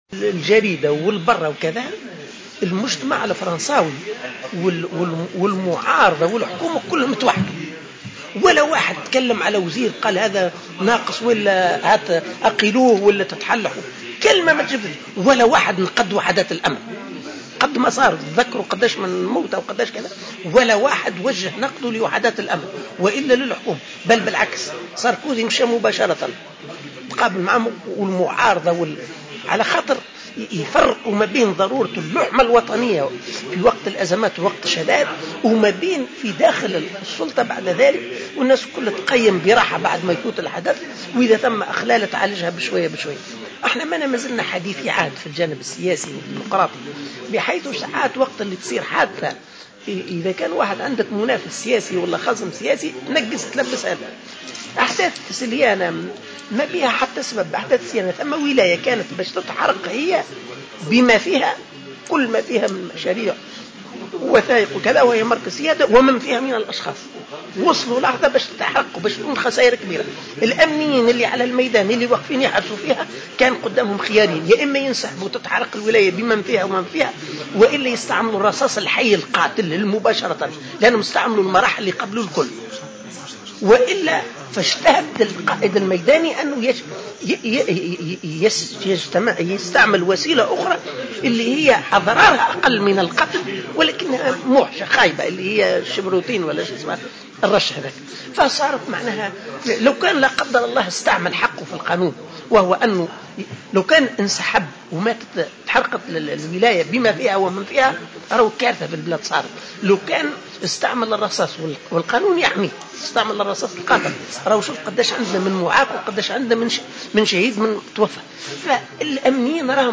Le secrétaire général du mouvement Ennahdha, Ali Larayedh, a défendu dans une déclaration accordée aujourd’hui à Jawhara FM le recours aux balles réelles par les unités de sécurité pour la dispersion des manifestants de Déhiba.